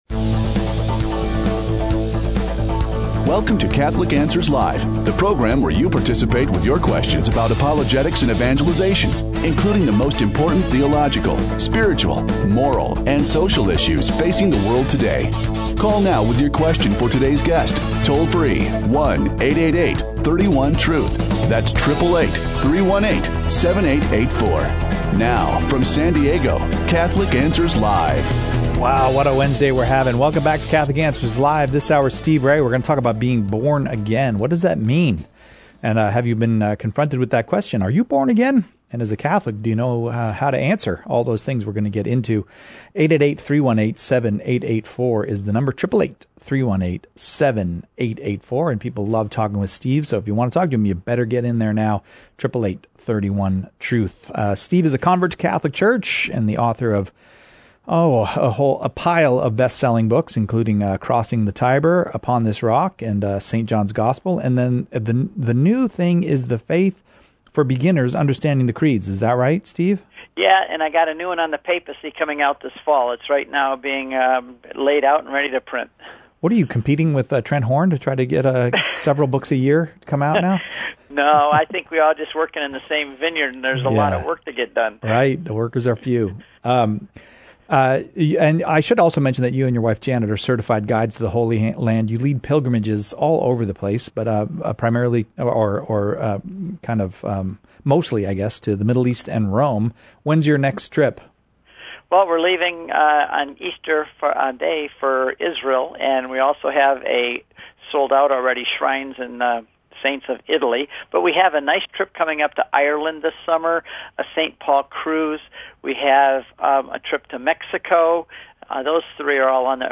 *There are phone quality issues present in the recording*